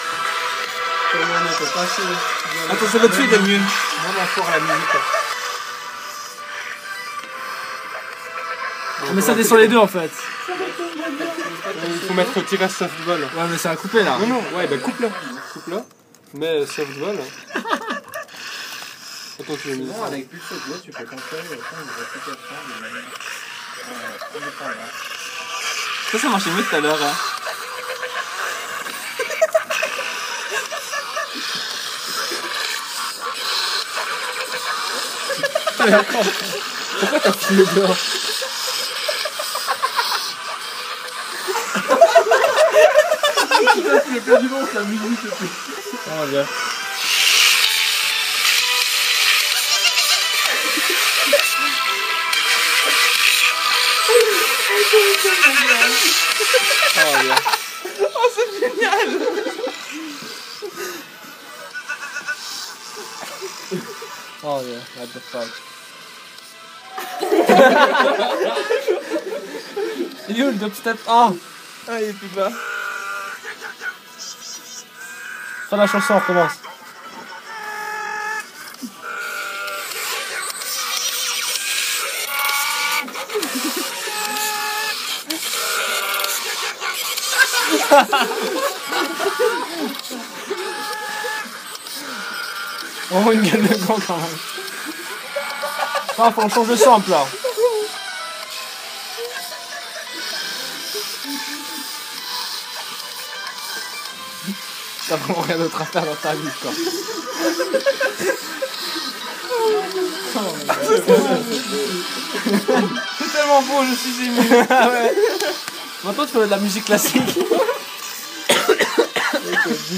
dubstep.m4a